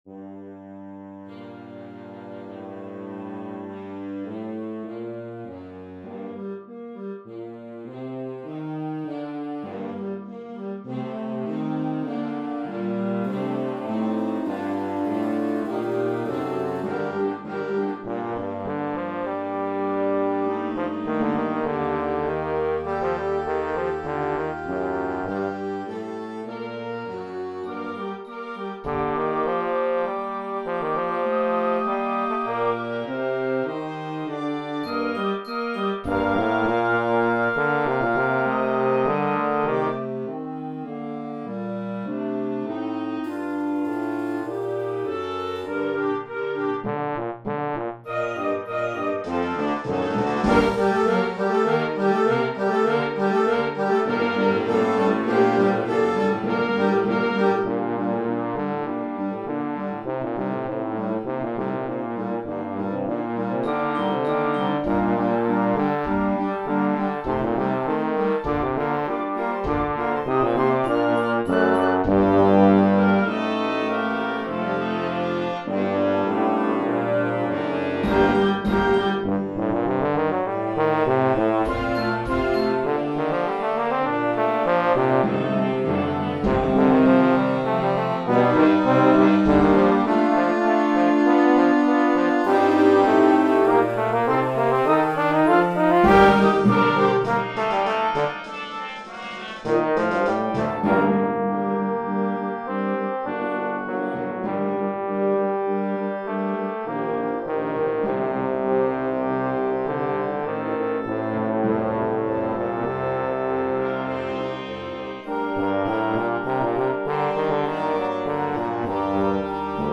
Concerto for Bass Trombone, Symphonic Band & Choir